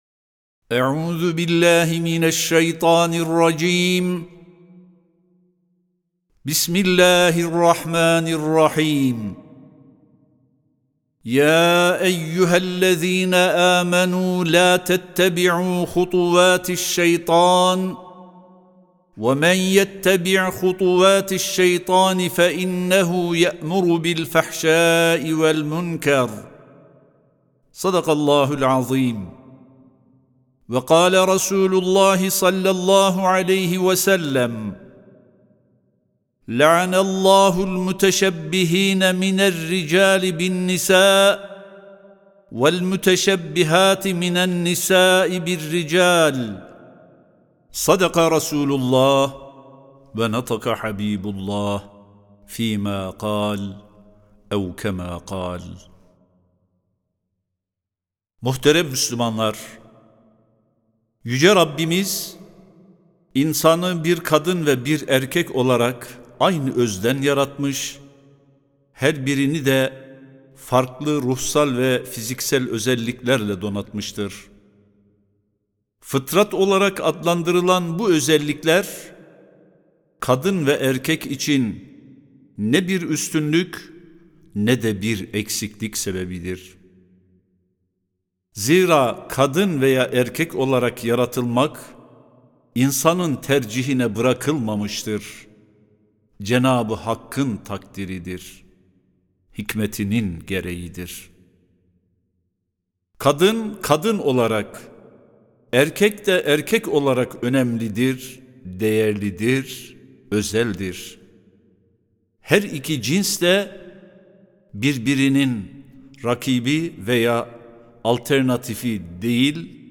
10.01.2025 Cuma Hutbesi: Fıtratı Korumak, Aileyi Korumaktır (Sesli Hutbe, Türkçe, İspanyolca, İngilizce, Rusça, Fransızca, İtalyanca, Almanca, Arapça)
Sesli Hutbe (Fıtratı Korumak, Aileyi Korumaktır).mp3